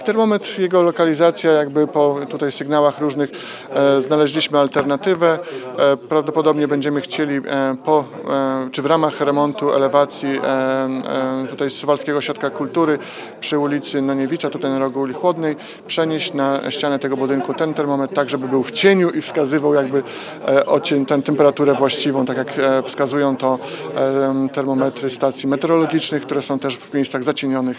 W odpowiedzi Łukasz Kurzyna, zastępca prezydenta Suwałk zapowiedział przeniesienie termometru w miejsce, gdzie będzie mógł spełniać swoją rolę.